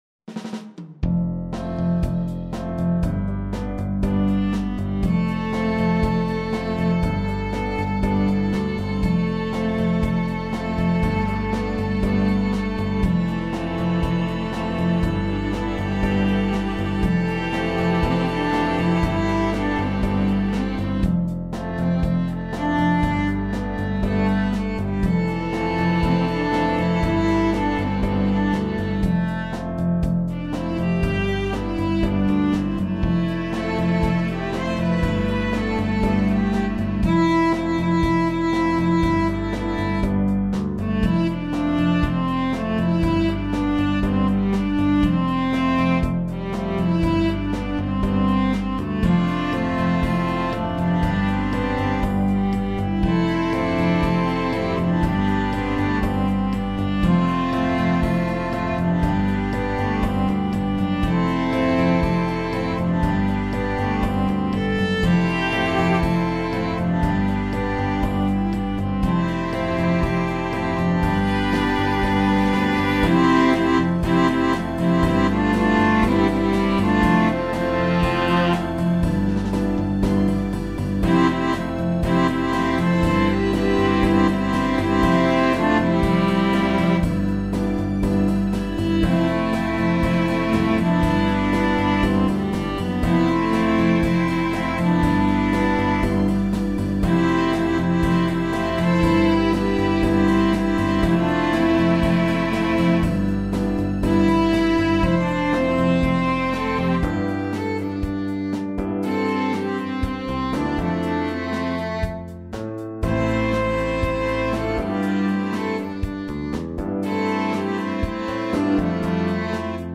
SSAA + piano/band